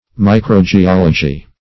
Search Result for " micro-geology" : The Collaborative International Dictionary of English v.0.48: Micro-geology \Mi`cro-ge*ol"o*gy\, n. [Micro- + geology.]
micro-geology.mp3